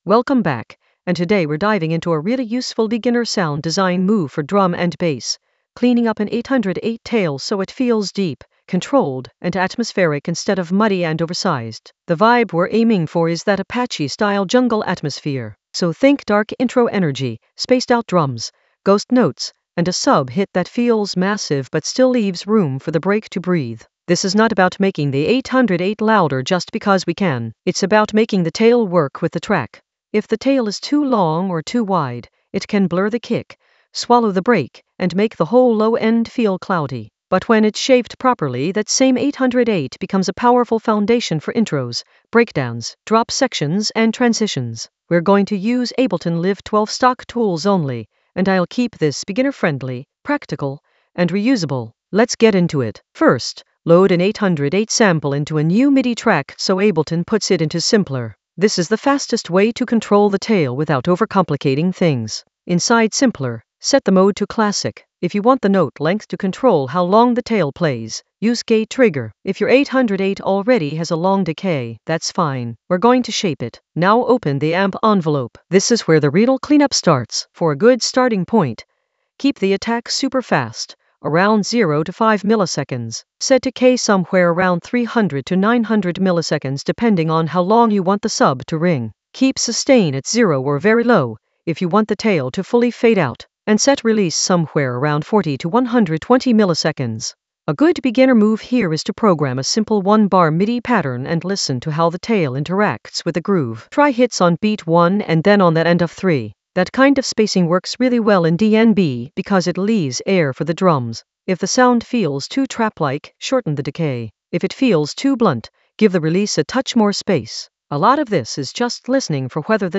An AI-generated beginner Ableton lesson focused on Apache: 808 tail clean for deep jungle atmosphere in Ableton Live 12 in the Sound Design area of drum and bass production.
Narrated lesson audio
The voice track includes the tutorial plus extra teacher commentary.